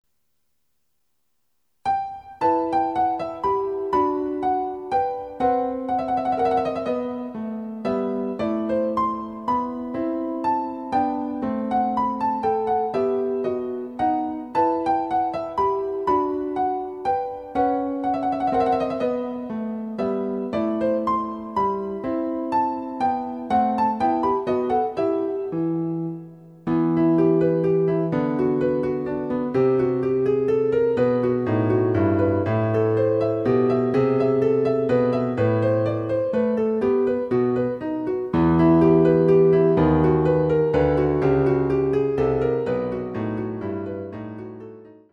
★ヴァイオリンの名曲をピアノ伴奏つきで演奏できる、「ピアノ伴奏ＣＤつき楽譜」です。
試聴ファイル（伴奏）
※ヴァイオリン奏者による演奏例は収録されていません。